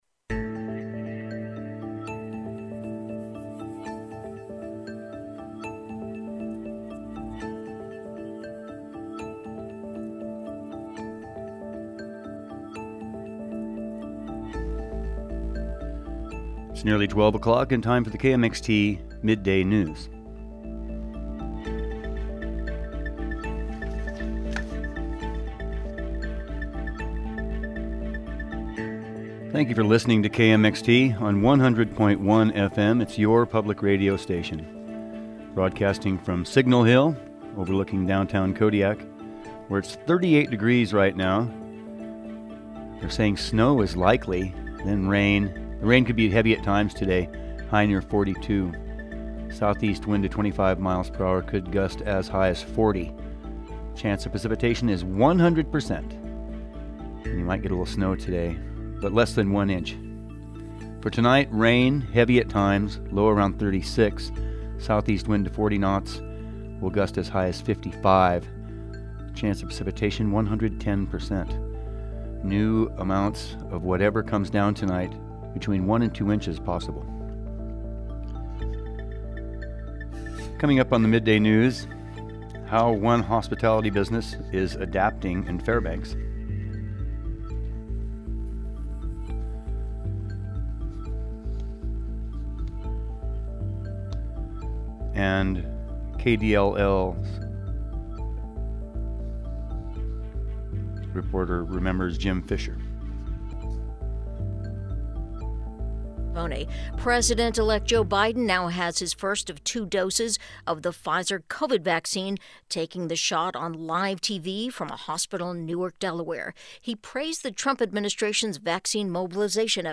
All that and more on today’s midday news report.